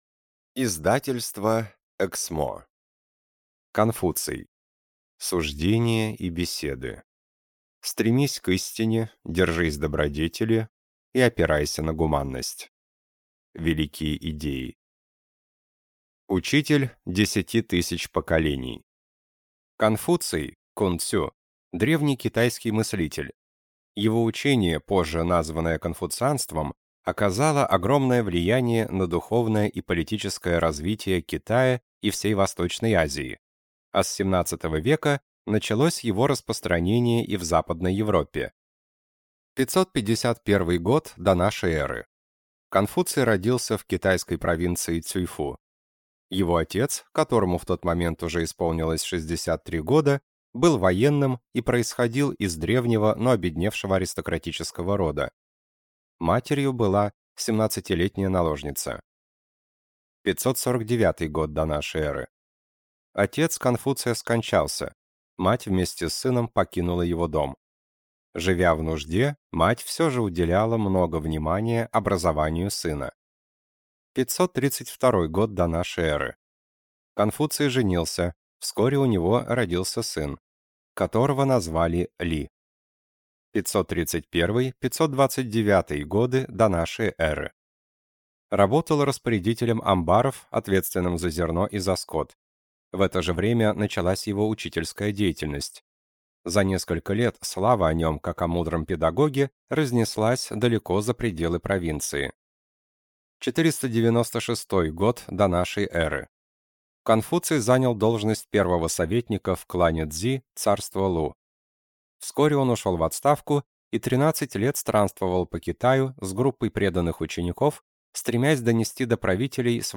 Аудиокнига Суждения и беседы | Библиотека аудиокниг